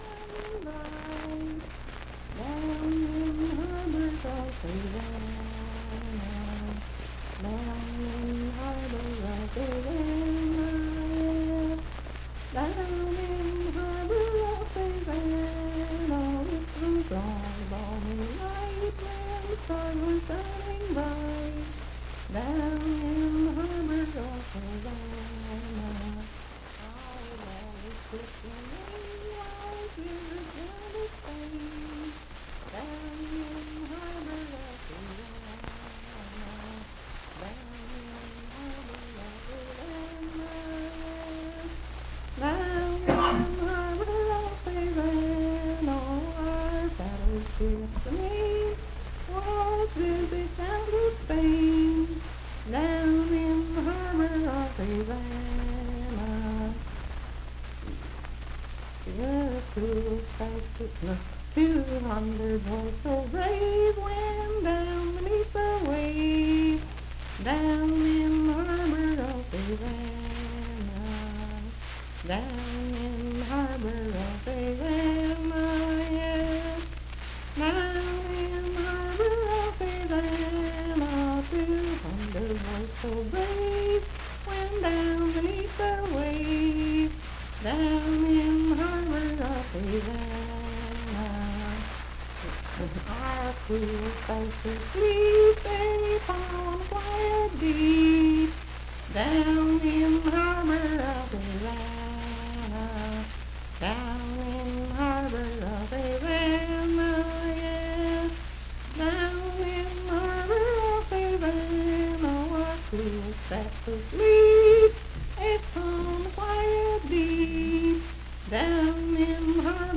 Unaccompanied vocal music
Verse-refrain 7(6w/R).
Voice (sung)
Hardy County (W. Va.), Moorefield (W. Va.)